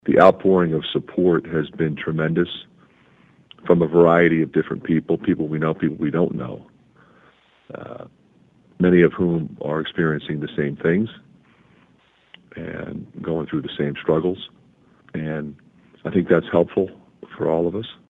That’s Iowa coach Fran McCaffery who says Patrick is still with the team.